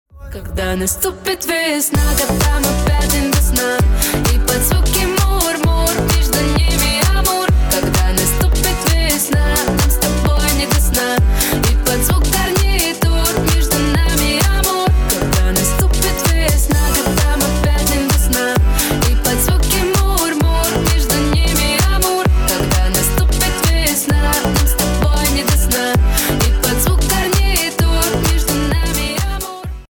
• Качество: 320, Stereo
поп
зажигательные
дуэт